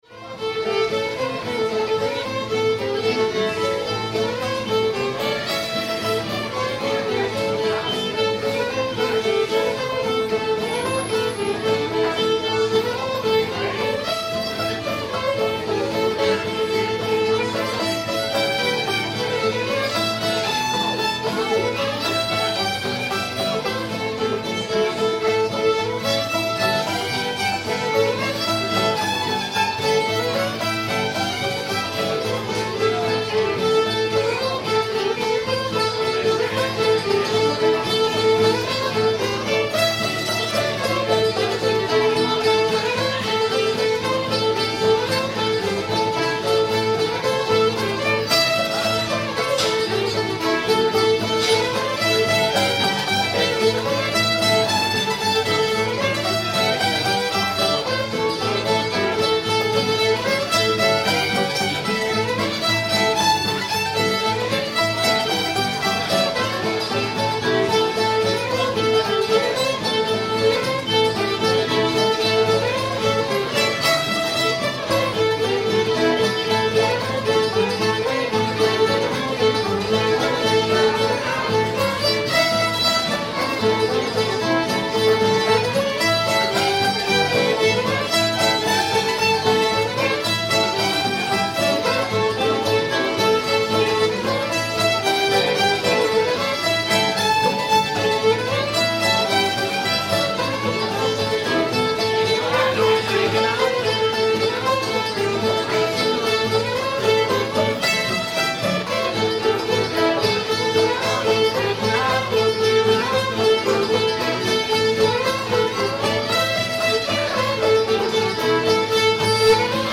greasy coat [A modal]